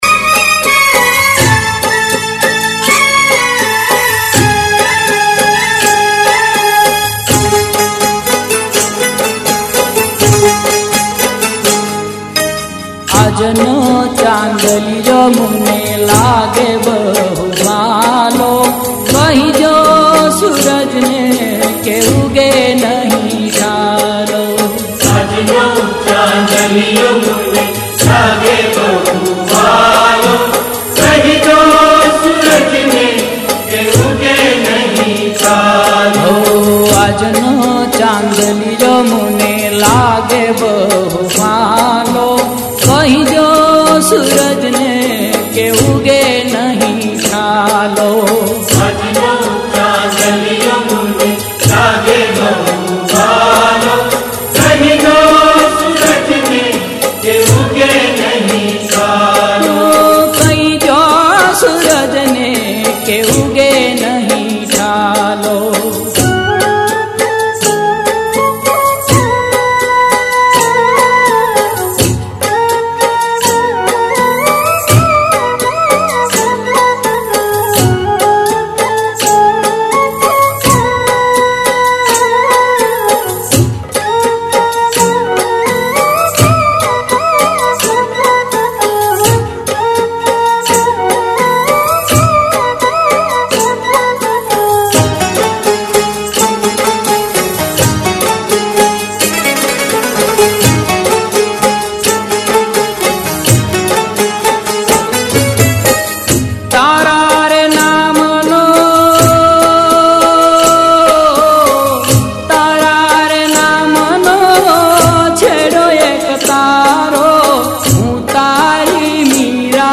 Non Stop Dandiya Mix